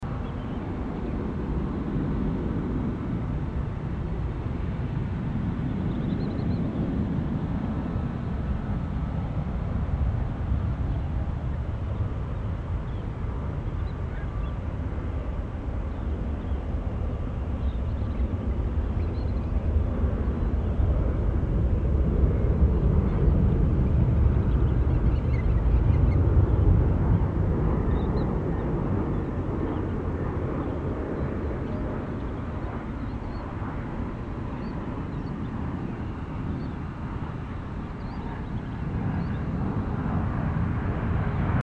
A perfect parallel landing between sound effects free download
A perfect parallel landing between an Alaska Embraer E175 and a United Airlines Boeing 737 MAX 9!